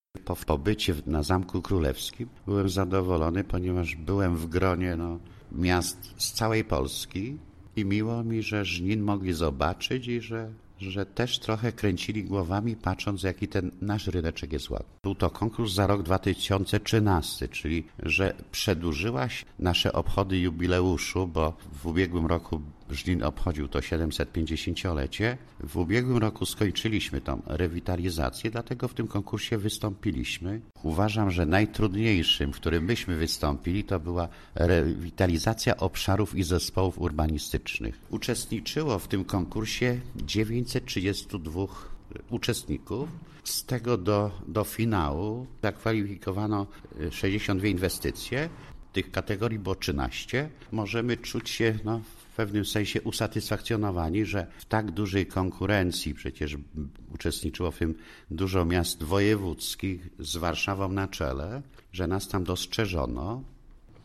Żniński Rynek walczył z 930 miastami, dlatego tym bardziej taki wynik cieszy, mówi Burmistrz Leszek Jakubowski.